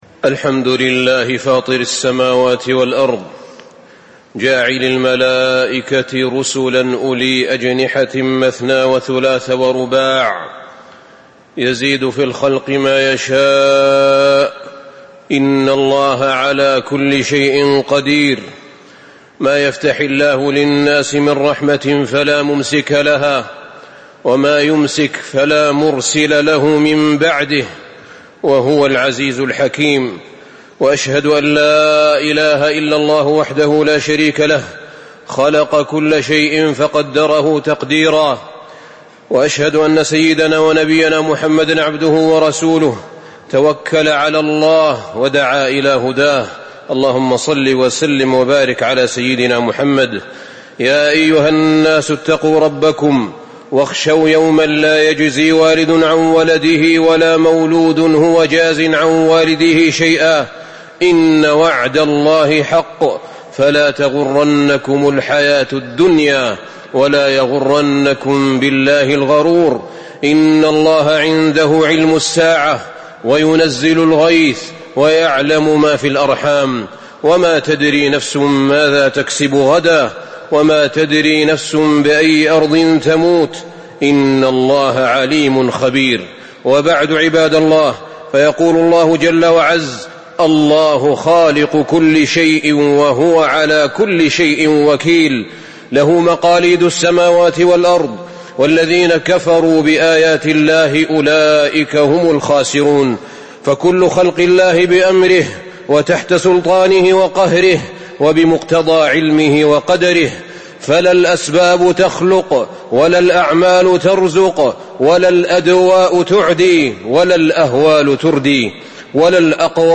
تاريخ النشر ٥ صفر ١٤٤٦ هـ المكان: المسجد النبوي الشيخ: فضيلة الشيخ أحمد بن طالب بن حميد فضيلة الشيخ أحمد بن طالب بن حميد تقدير الله تعالى للأسباب